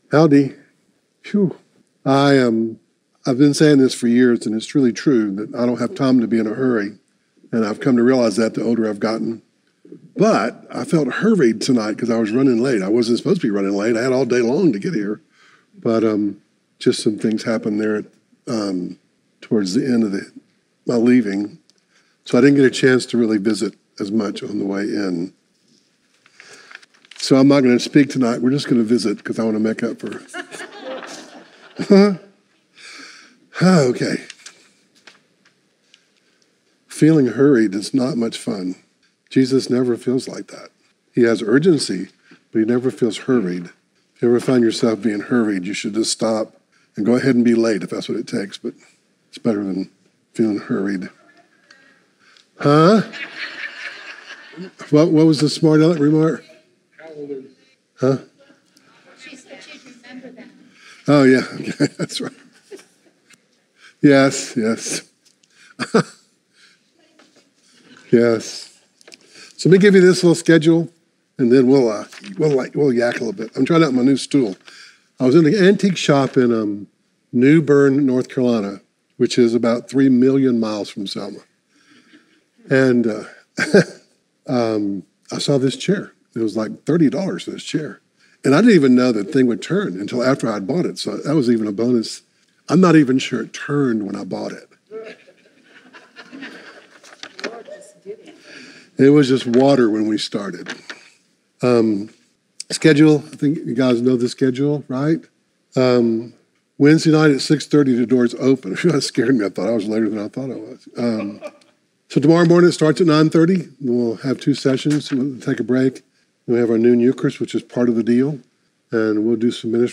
Romans 6:1-10 Service Type: Conference